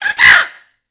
文件:Bird wake up.ogg